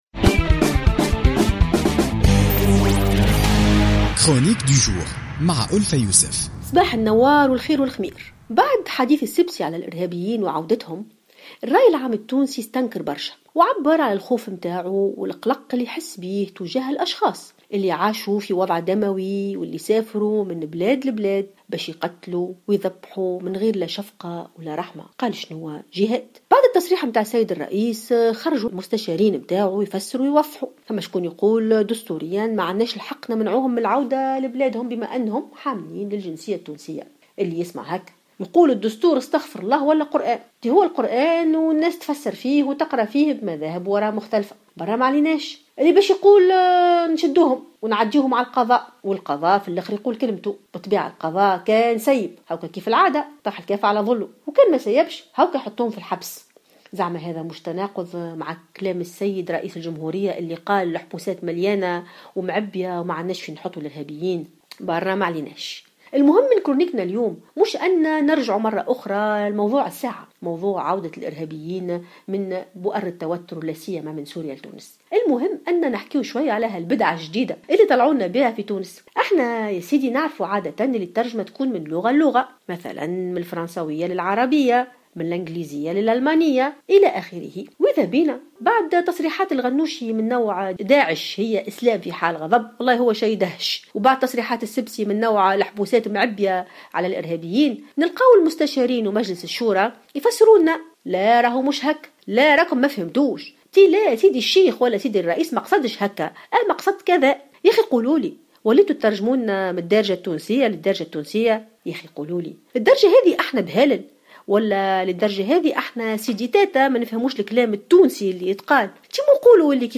تحدثت الباحثة ألفة يوسف في افتتاحية اليوم الاثنين 12 ديسمبر 2016 عن تصريح رئيس الجمهورية الباجي قائد السبسي الذي أثار جدلا والمتعلق بعودة الإرهابيين من بؤر التوتر خاصة الذين كانوا يقاتلون في سوريا.